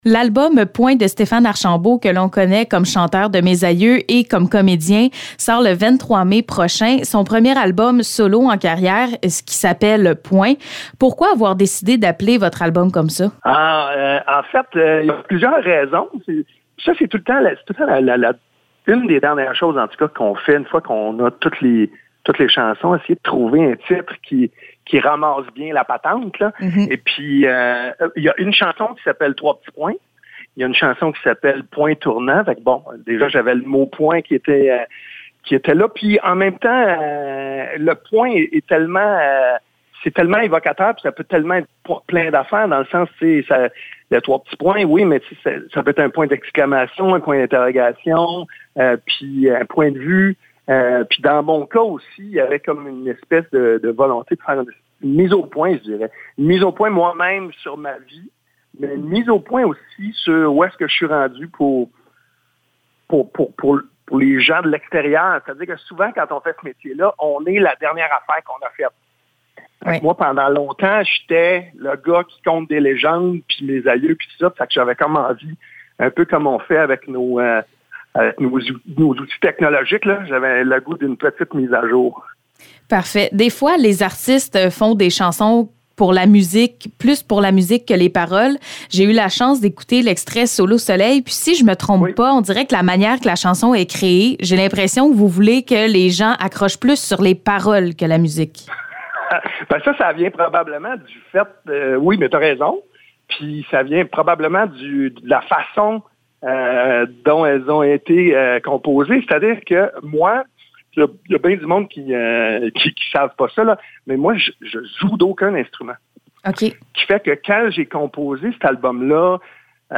Entrevue Stéphane Archambault
Entrevue avec Stéphane Archambault concernant son nouvel album solo Point.